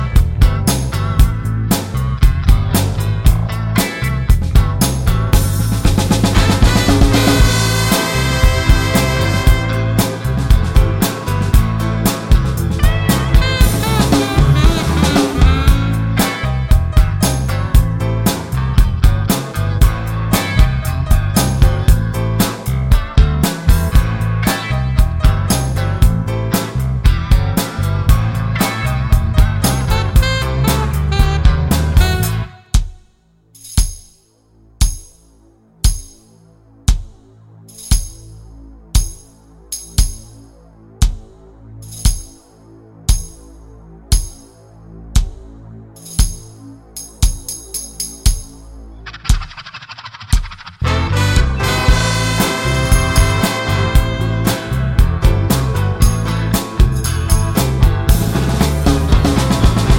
no Backing Vocals Soundtracks 2:57 Buy £1.50